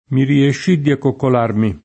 riuscire (pop. riescire) v.; riesco [ri-$Sko], ‑sci — coniug. come uscire — es. delle variaz. tra riu- e rie- fuor d’accento: mi riuscì di far calare giù l’altro capo [mi riušš& ddi far kal#re J2 ll altro k#po] (Vamba); mi riescì di accoccolarmi [